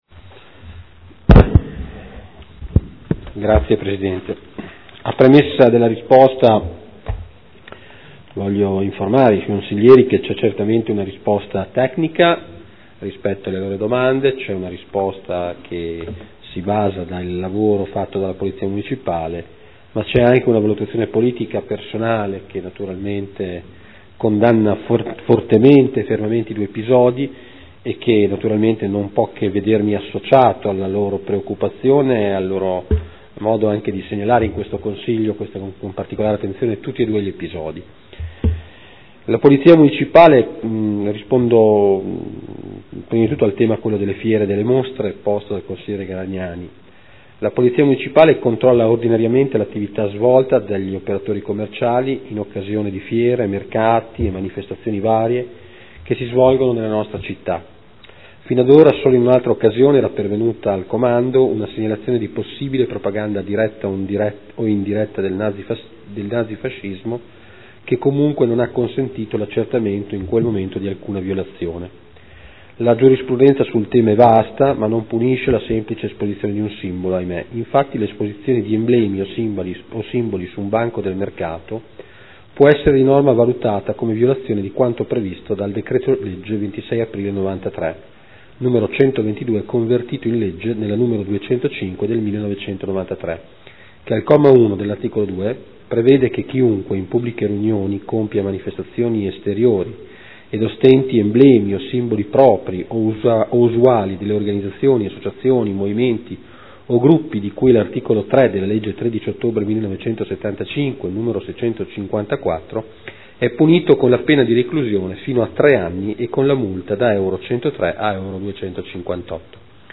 Marino Antonino — Sito Audio Consiglio Comunale